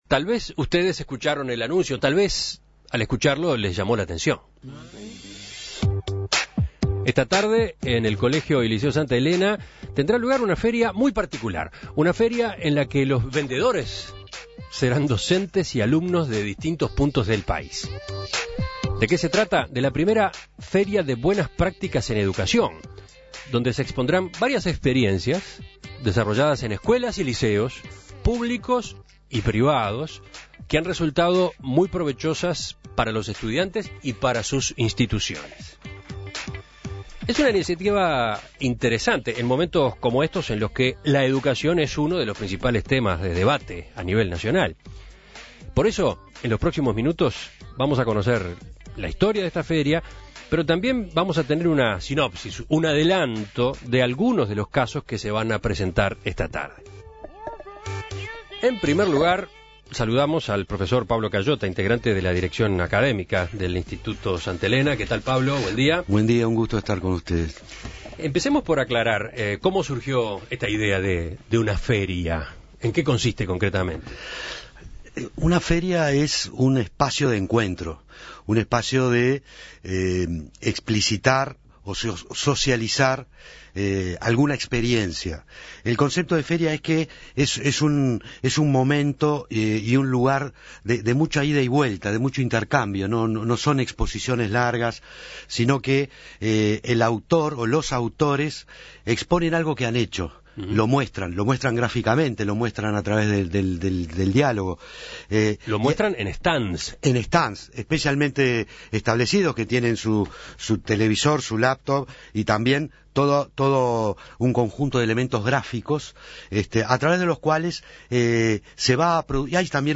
En la entrevista también participaron dos responsables de los proyectos que se pueden ver en la feria.